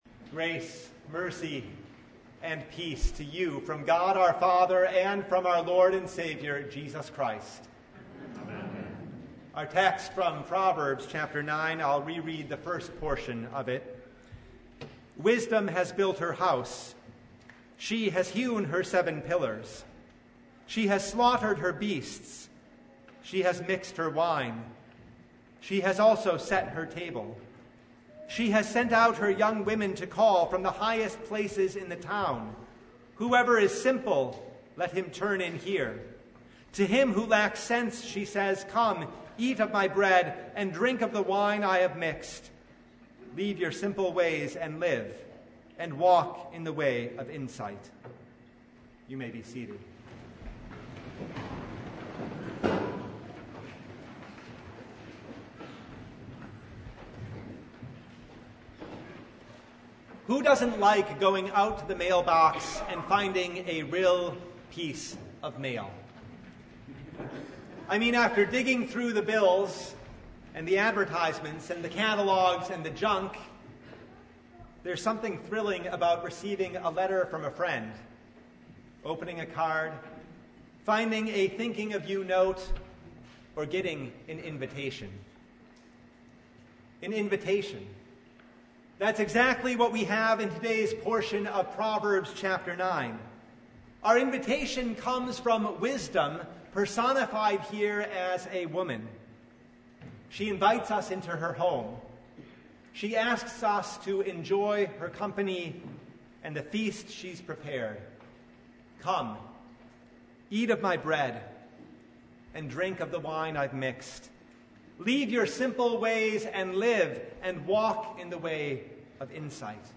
Proverbs 9:1–12 Service Type: Sunday Are you simple?